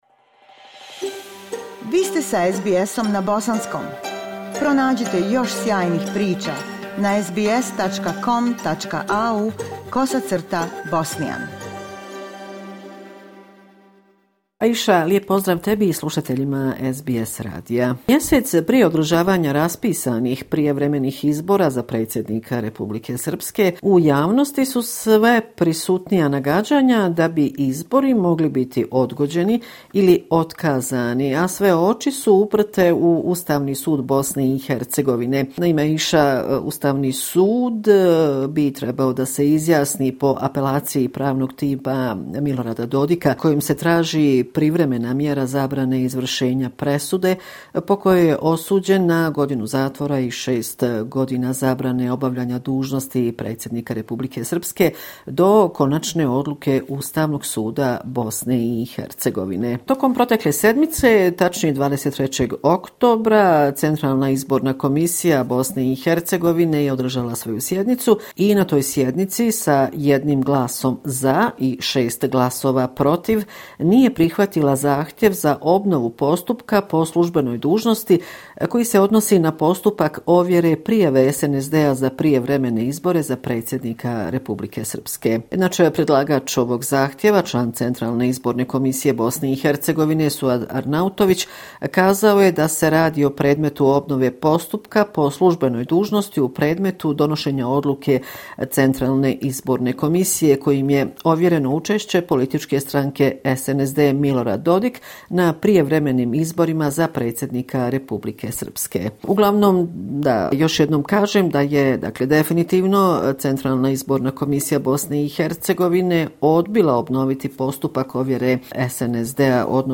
Redovni sedmični izvještaj